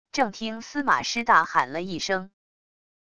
正听司马师大喊了一声wav音频